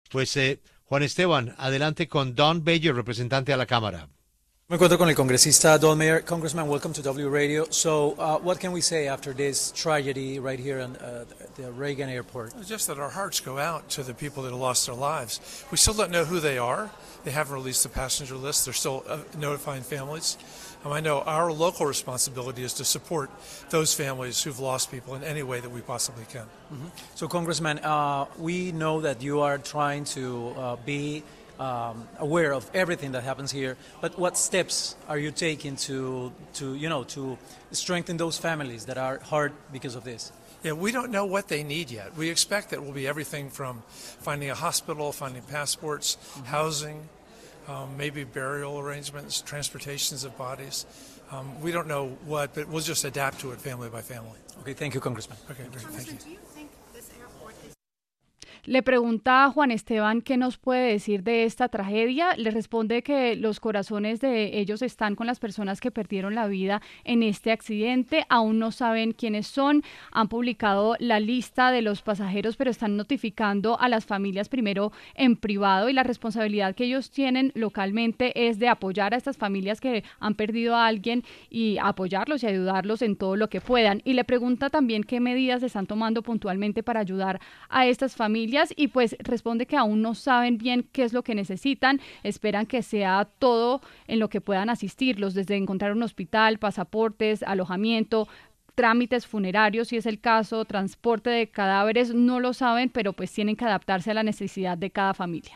Para hablar del tema, pasó por los micrófonos de La W, con Julio Sánchez Cristo, Don Beyer, congresista de Estados Unidos y representante por Virginia, para explicar qué se tiene pensado hacer para ayudar a las familias de las personas fallecidas en la emergencia.